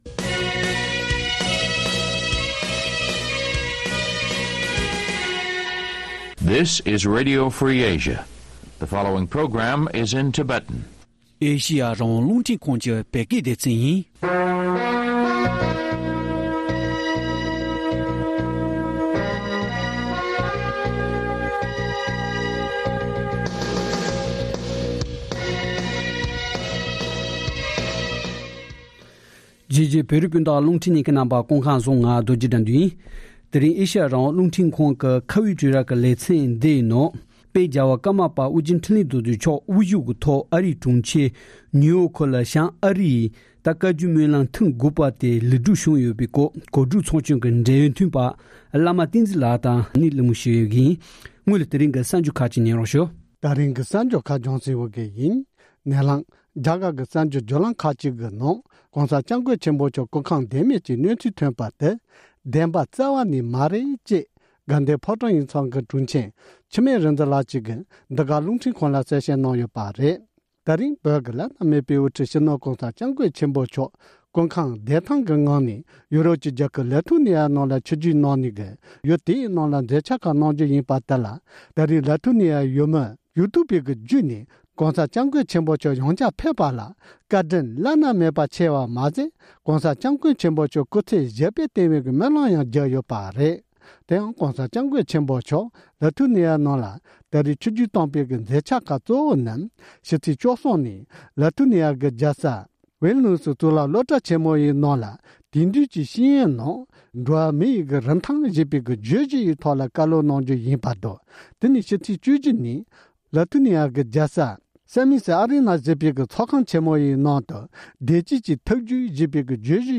དཔལ་རྒྱལ་དབང་ཀརྨ་པ་མཆོག་གིས་ཇོ་བོ་རྗེས་མཛད་པའི་མར་མེ་སྨོན་ལམ་རྟ་དབྱངས་དང་བཅས་གསུངས་འདོན་གནང་བ།